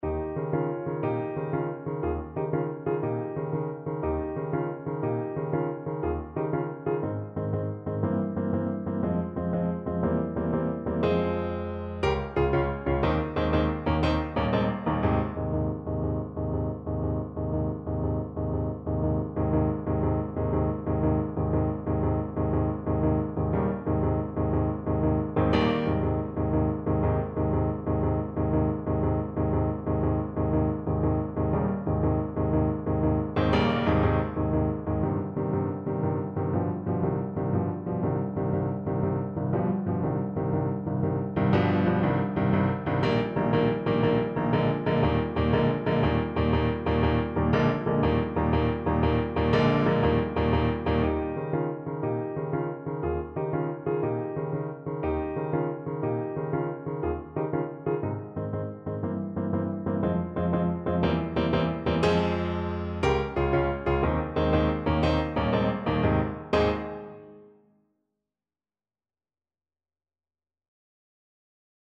Neapolitan song
6/8 (View more 6/8 Music)
Classical (View more Classical Tenor Saxophone Music)